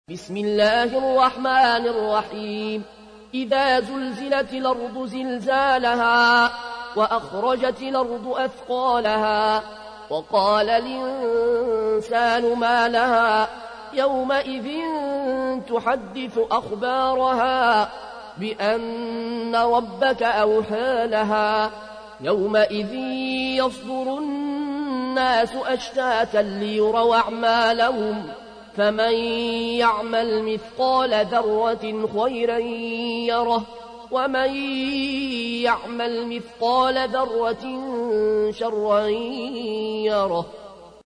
تحميل : 99. سورة الزلزلة / القارئ العيون الكوشي / القرآن الكريم / موقع يا حسين